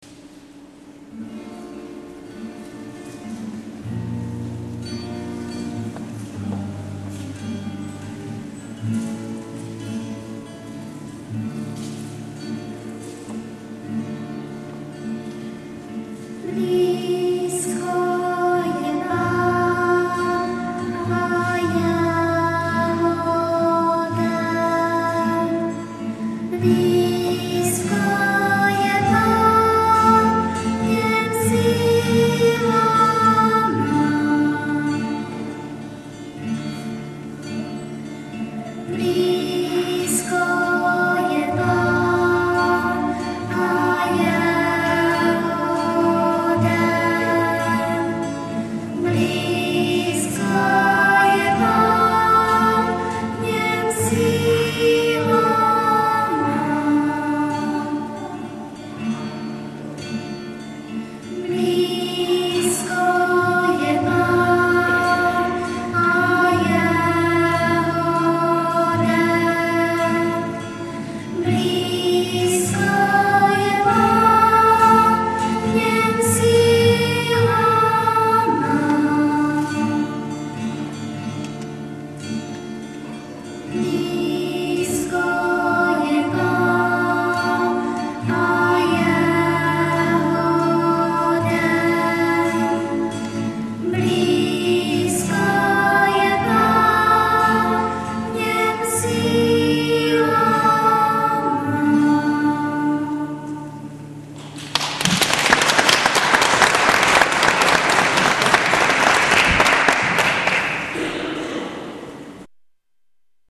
ADVENTNÍ KONCERT
Březová … kostel sv. Cyrila a Metoděje ... neděla 9.12.2007
BLÍZKO JE PÁN ... březovská schola
... pro přehrátí klepni na názvy písniček...bohužel ně skomírala baterka v MD ...